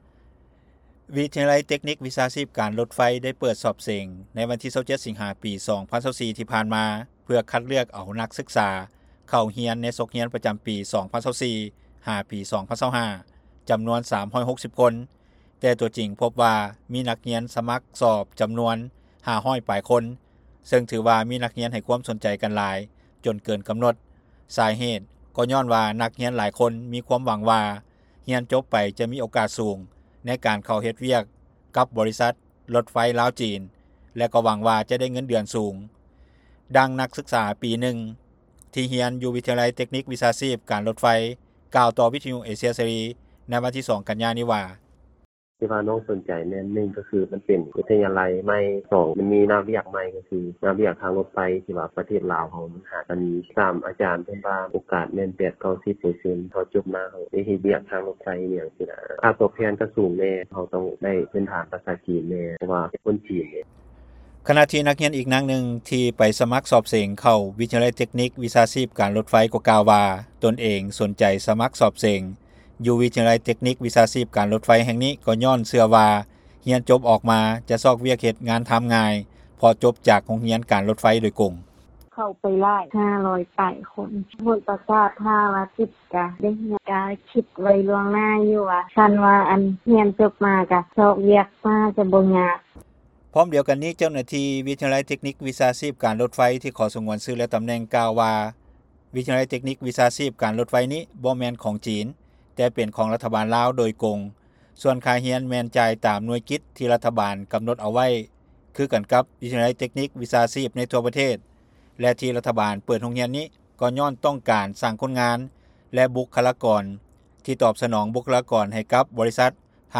ຂະນະທີ່ນັກຮຽນອີກນາງໜຶ່ງ ທີ່ໄປສະມັກສອບເສັງເຂົ້າວິທະຍາໄລ ເຕັກນິກ-ວິຊາຊີບການລົດໄຟກໍ່ກ່າວວ່າທີ່ຕົນເອງ ສົນໃຈສະມັກສອບເສັງຢູ່ວິທະຍາໄລເຕັກນິກ-ວິຊາຊີບການລົດໄຟ ແຫ່ງນີ້ກໍ່ຍ້ອນເຊື່ອວ່າຮຽນຈົບອອກມາຈະຊອກວຽກງານທຳງ່າຍ ເພາະຈົບຈາກໂຮງຮຽນການລົດໄຟໂດຍກົງ.